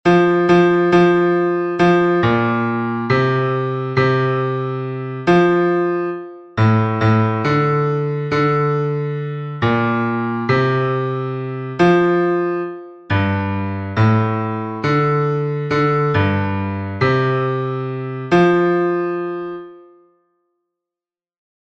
Fichier son Bass